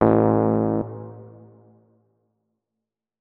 keyboard / electric_piano